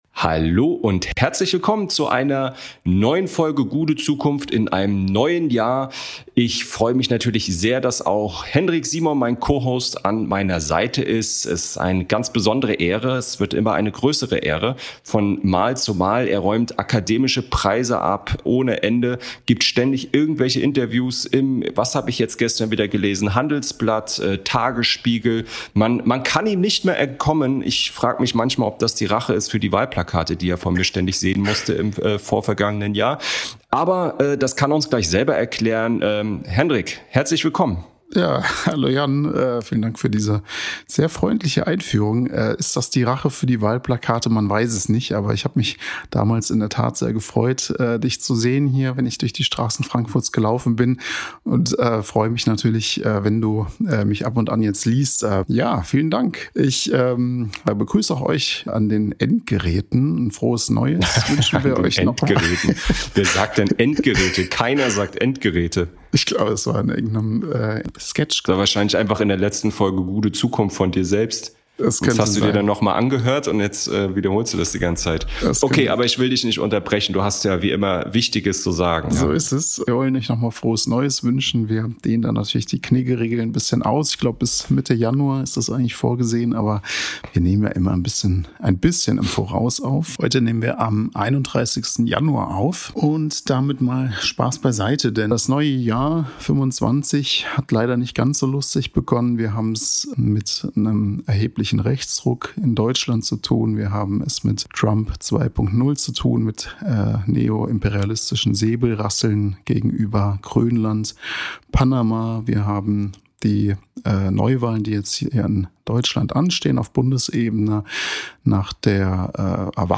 31:30 min Interview mit Ursula Busch, SPD-Fraktionsvorsitzende im Römer